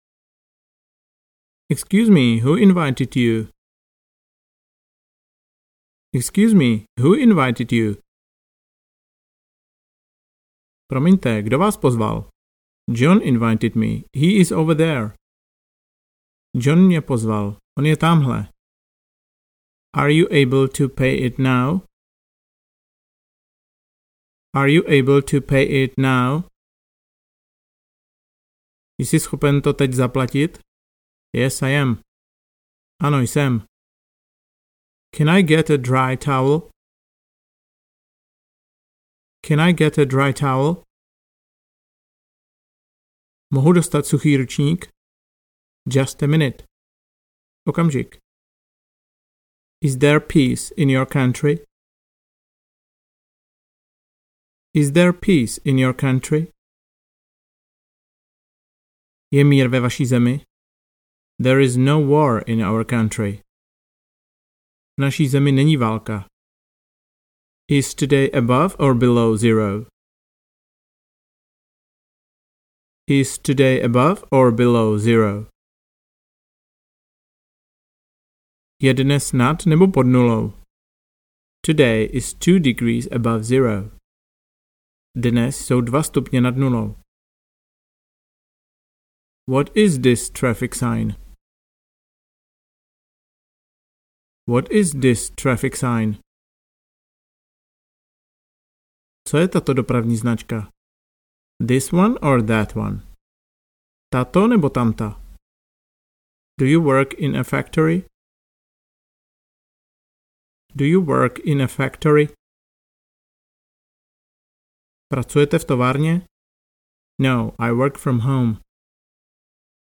Audiokniha
Tempo je už hodně podobné tomu, jako kdyby na vás někdo ”spustil” anglicky na ulici, v hotelu nebo v restauraci.
Ale ne rodilý mluvčí.
V celkem svižném tempu se střídá otázka s odpovědí v angličtině a v češtině.
Máte možnost v mezerách opakovat otázky a učit se mluvit.